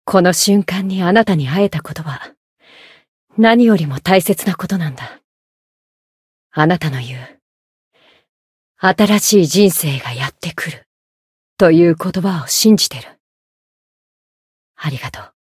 灵魂潮汐-迦瓦娜-春节（送礼语音）.ogg